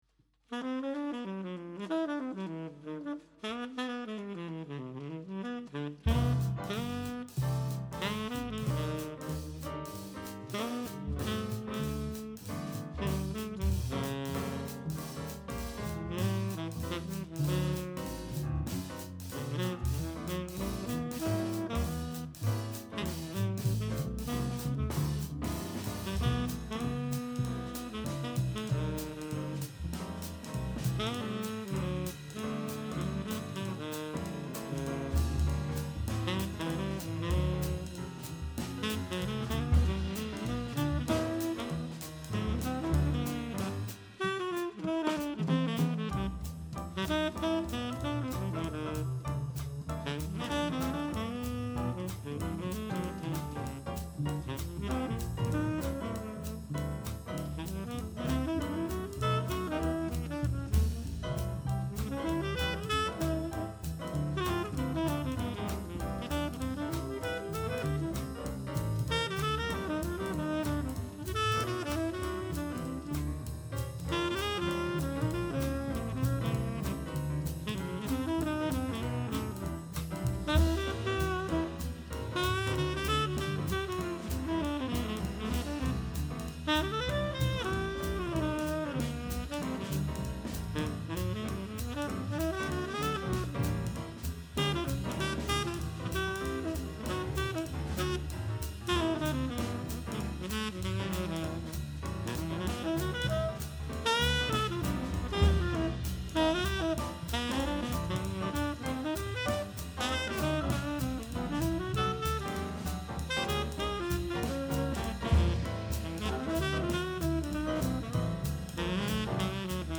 vocals
sax, tb …
piano
bass
drums
Probe vom 24.1.26